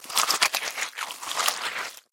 Звуки чудовища
Звук чудовища, поедающего труп